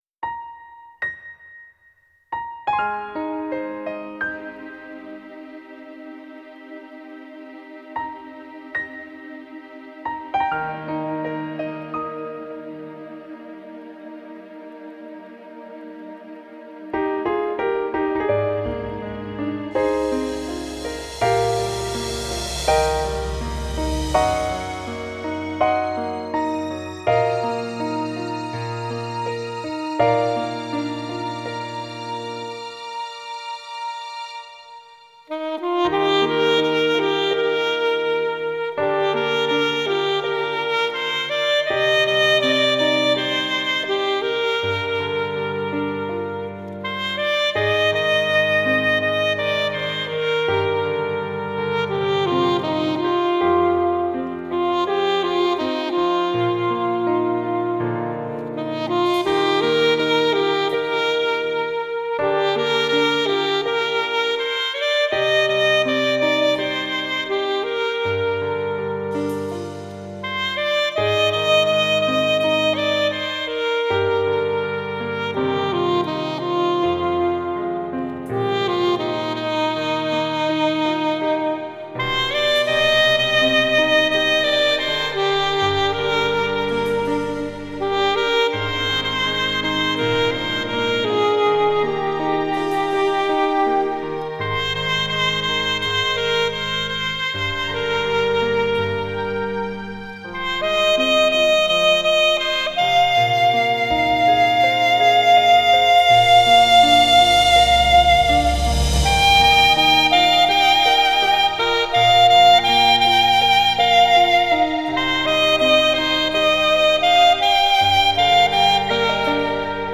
2周前 纯音乐 11